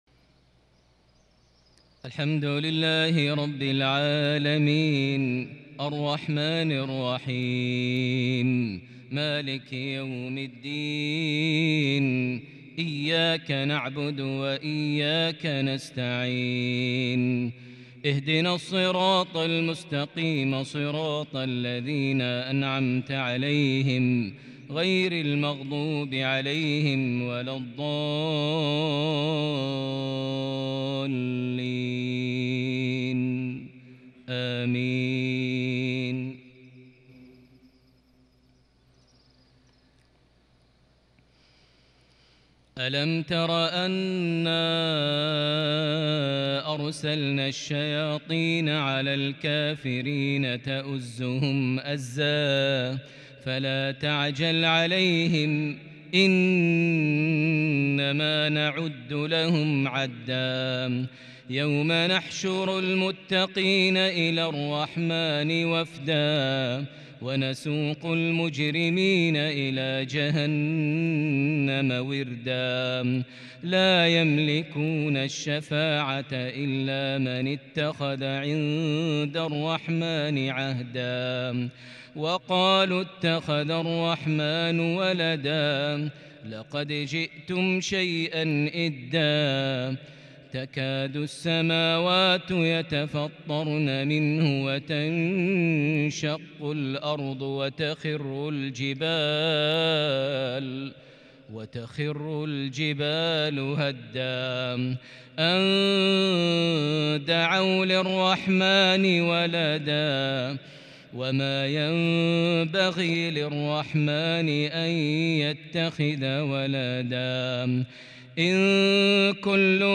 صلاة المغرب للشيخ ماهر المعيقلي 6 ذو الحجة 1442 هـ
تِلَاوَات الْحَرَمَيْن .